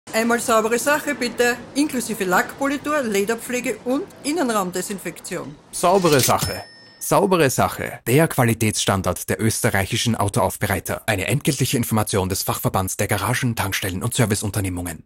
Zweiter Baustein: In NÖ läuft aktuell ein 15-sek Radiospot, der insbesondere die Zielgruppe der Autoaufbereiter:innen adressiert.